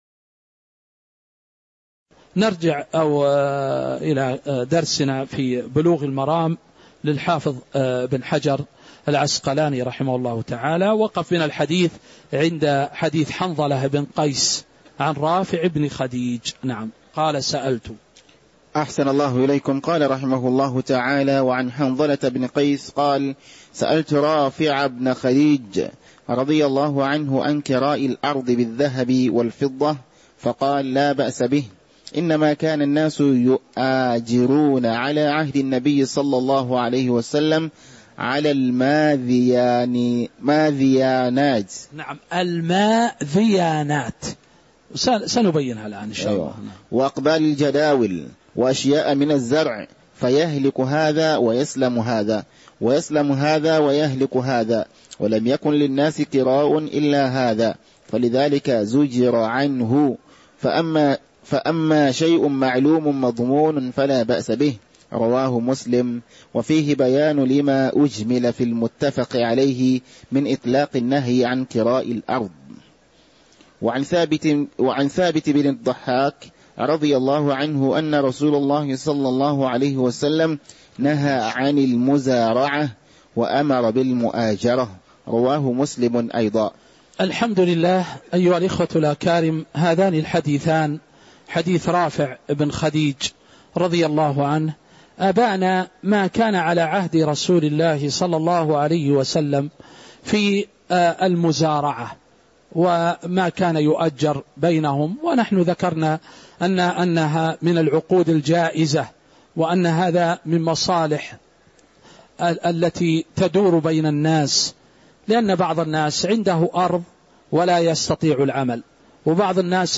تاريخ النشر ١٤ جمادى الآخرة ١٤٤٦ هـ المكان: المسجد النبوي الشيخ